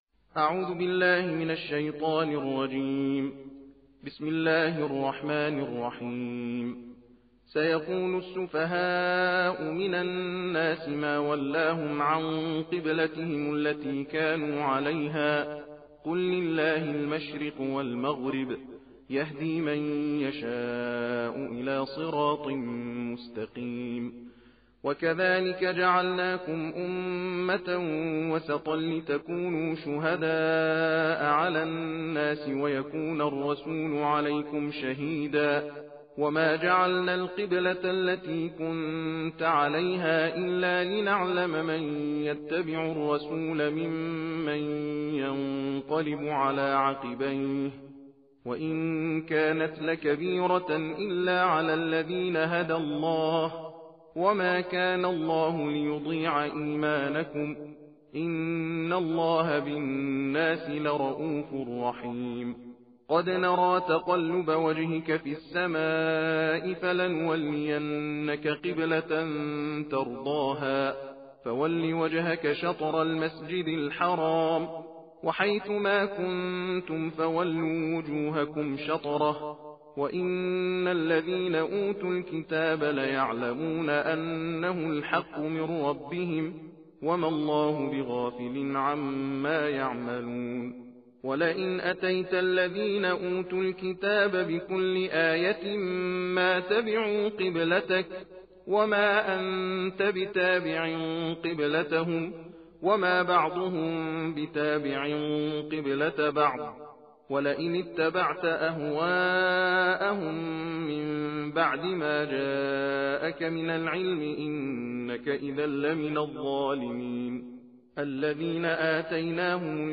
تلاوت قرآن کريم